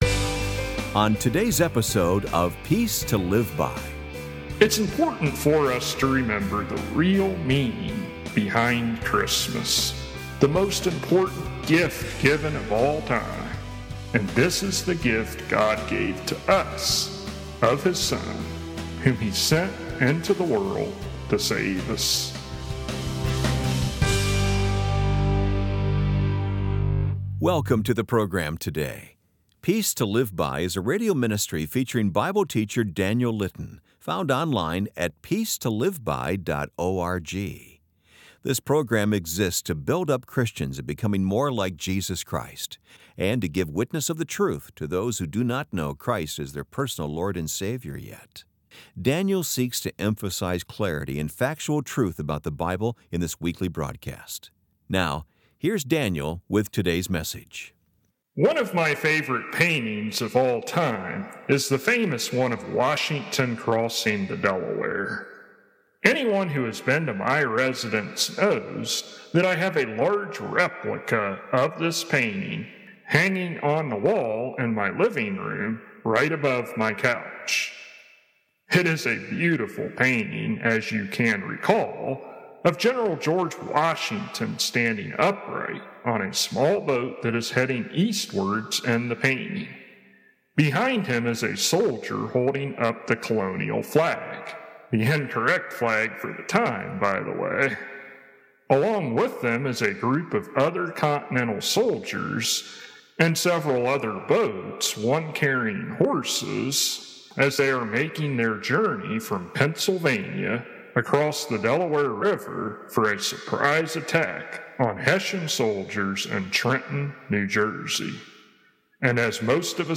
[Transcript may not match broadcasted sermon word for word, and contains extra material that was cut from the broadcast due to time constraints] One of my favorite paintings of all time is the famous one of ‘Washington Crossing the Delaware.’